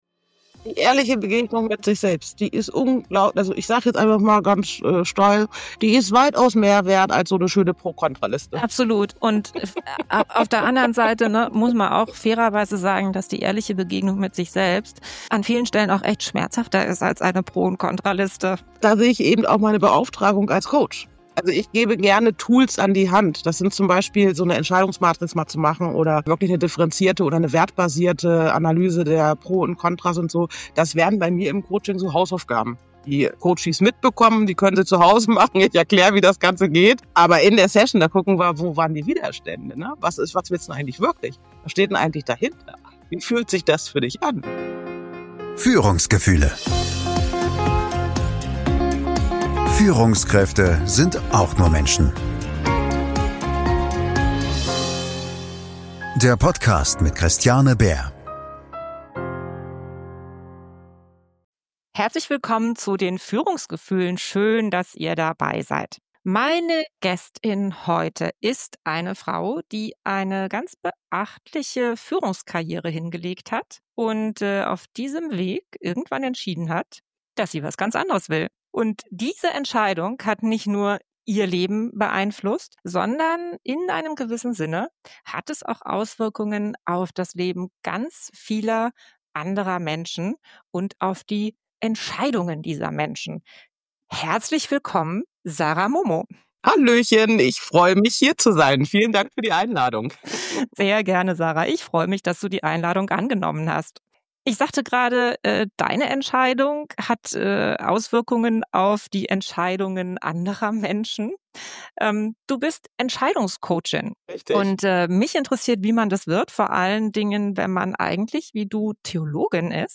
Mut zur Leere – Entscheidungen, die das Leben verändern - Gespräch